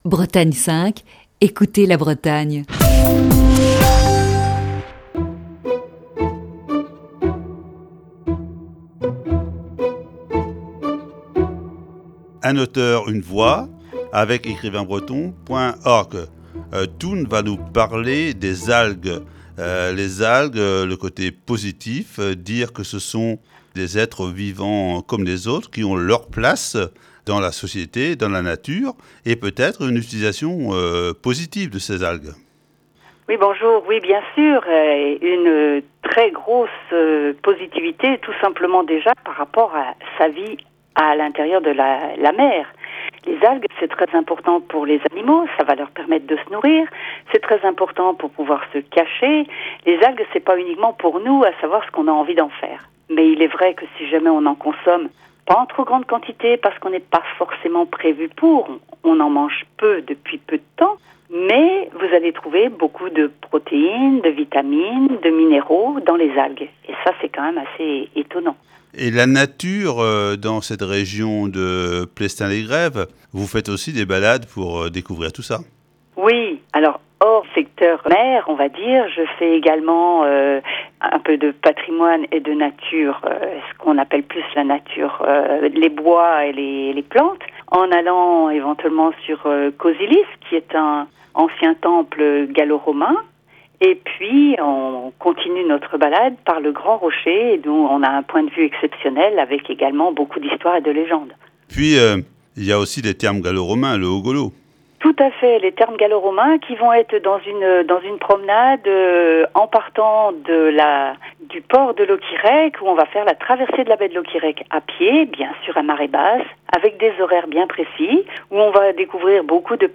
Émission du 31 août 2021.